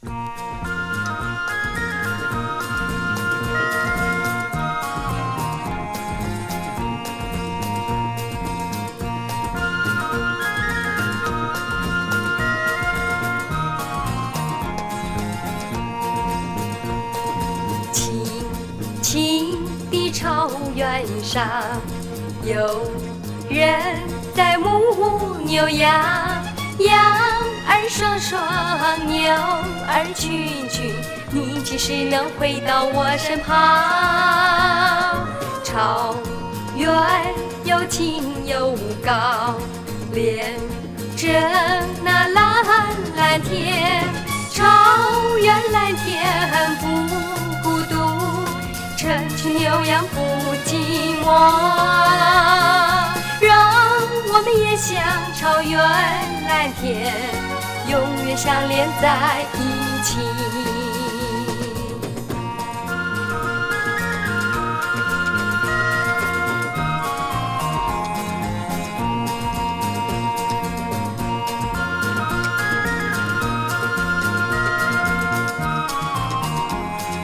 台湾出身のマンドポップ・シンガー
どこか南米ポップスを感じる？